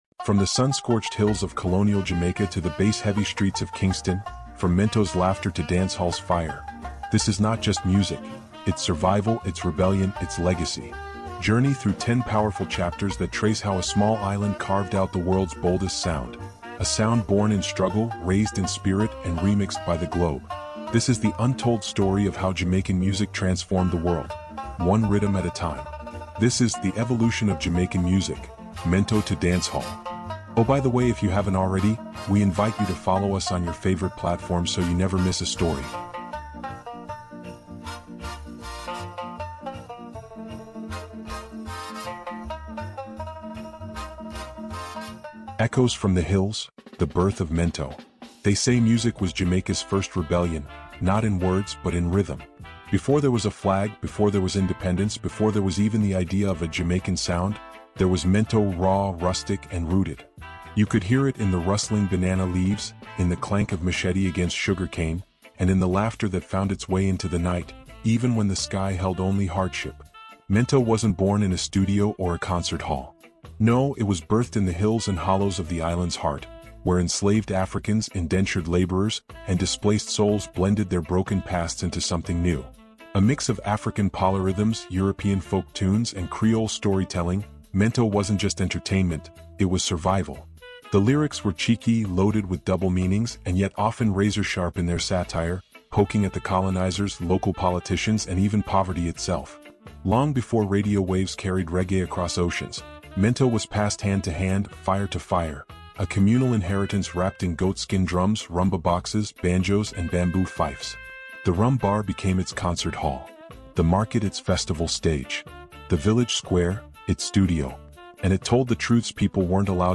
The Evolution of Jamaican Music: Mento to Dancehall is a masterfully crafted audiobook documentary designed for history experts, travel enthusiasts, and lovers of cultural resilience. Dive into the heart of Caribbean history as this immersive series explores how Jamaican music evolved from humble mento to global dancehall dominance — a sonic timeline rich with the rhythms of ska, rocksteady, reggae, dub, and more. Told through immersive storytelling and narrated with emotional realism, this documentary captures the power of Jamaican music not just as entertainment, but as a tool of resilience, identity, and political resistance.